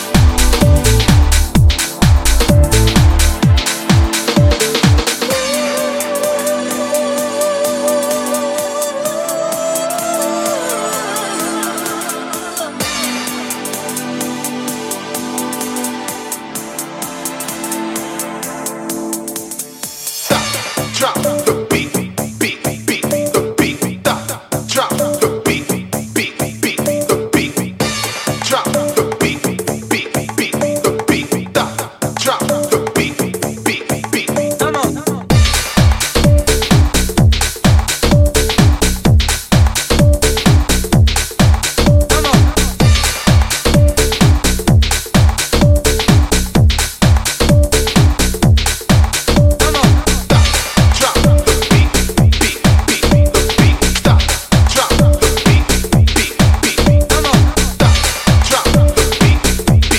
渋いベースラインの反復やスタブで淡々と煽る